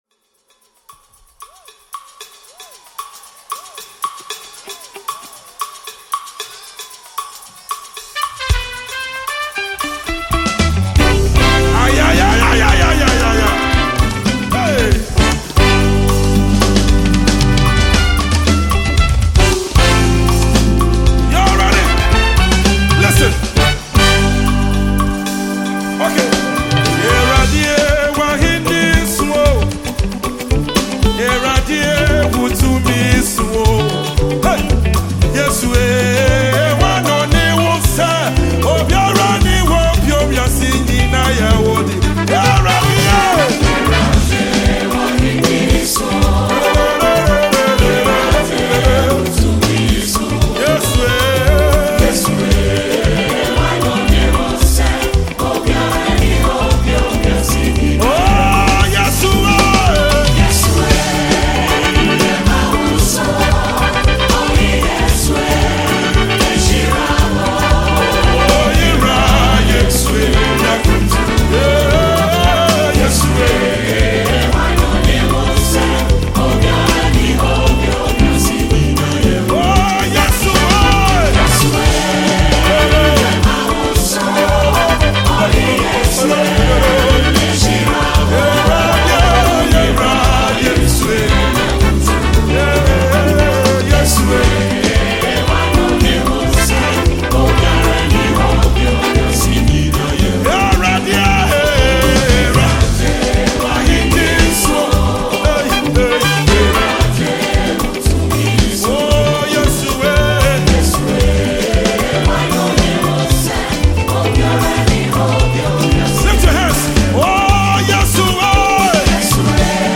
January 17, 2025 Publisher 01 Gospel 0